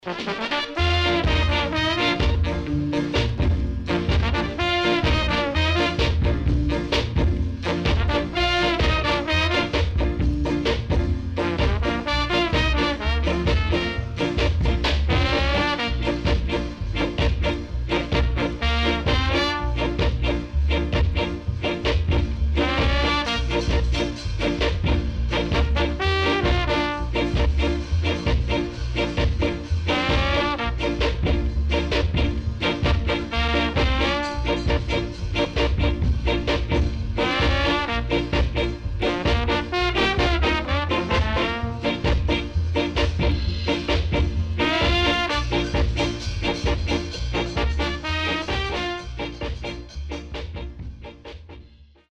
SIDE B:少しノイズ入りますが良好です。